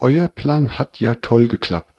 sample04-TensorFlowTTS.wav